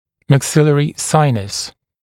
[mæk’sɪlərɪ ‘saɪnəs][мэк’силэри ‘сайнэс]верхнечелюстной синус